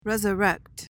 PRONUNCIATION:
(rez-uh-REKT)